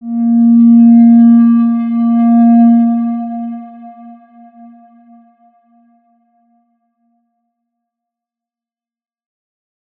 X_Windwistle-A#2-mf.wav